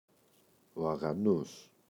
αγανός [aγa’nos]